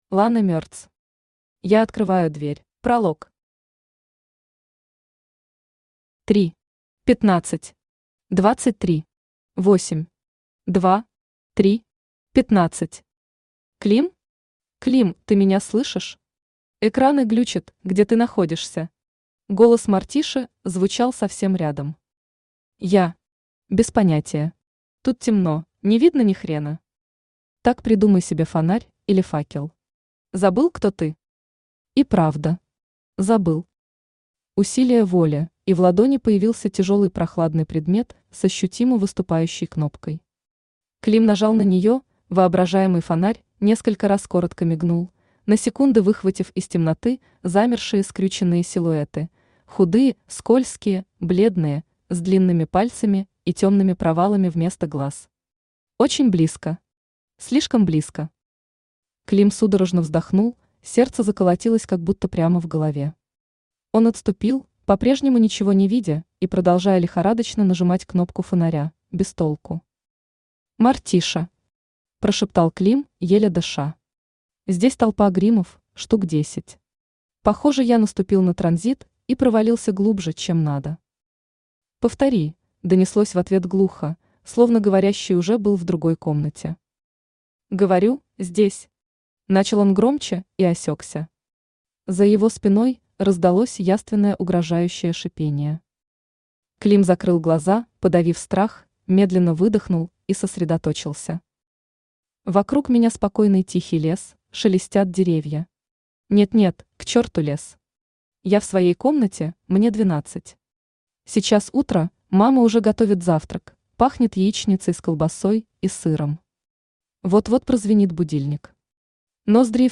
Аудиокнига Я открываю дверь…
Автор Лана Мерц Читает аудиокнигу Авточтец ЛитРес.